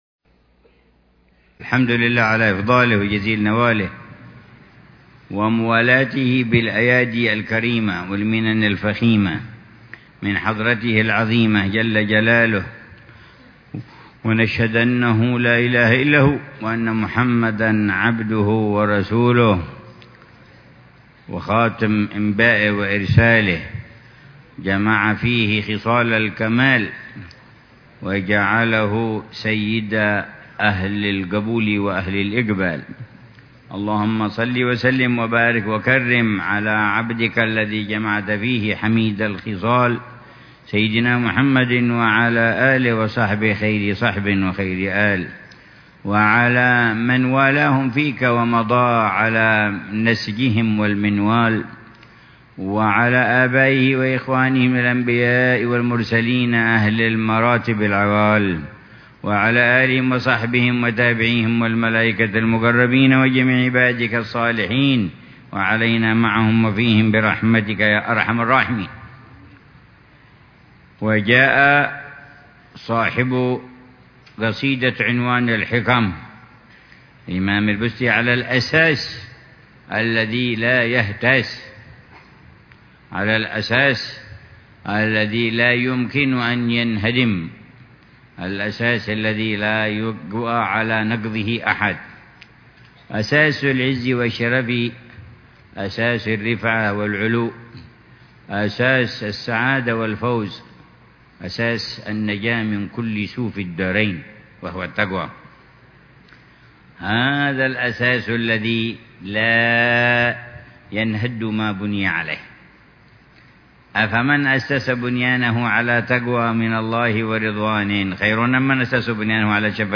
الدرس السادس من شرح العلامة الحبيب عمر بن محمد بن حفيظ لقصيدة الإمام أبي الفتح البستي - عنوان الحِكم ، التي مطلعها: ( زيادة المرء في دنيا